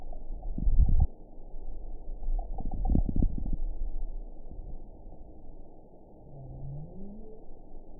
event 921953 date 12/23/24 time 07:34:39 GMT (4 months, 1 week ago) score 8.52 location TSS-AB03 detected by nrw target species NRW annotations +NRW Spectrogram: Frequency (kHz) vs. Time (s) audio not available .wav